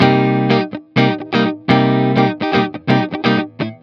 04 GuitarFunky Loop C.wav